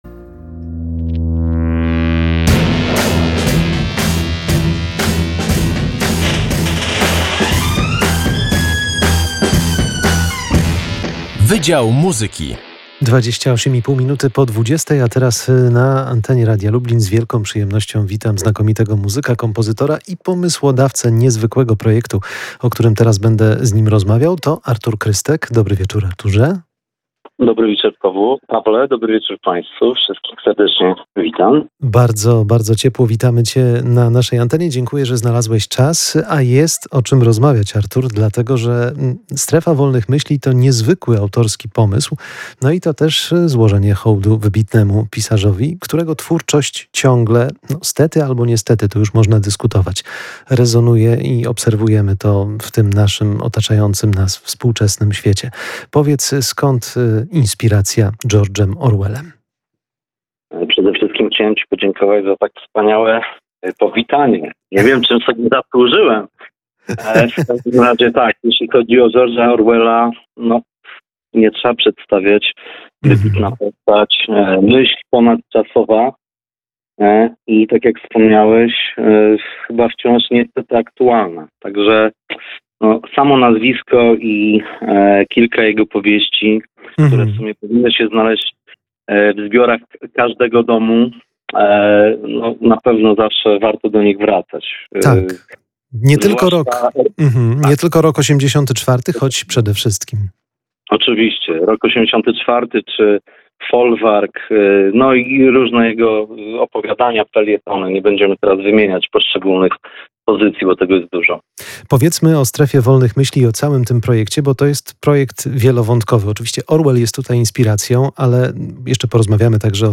Wydział Muzyki: Strefa Wolnych Myśli, czyli muzyczne odczytanie Orwella [POSŁUCHAJ ROZMOWY]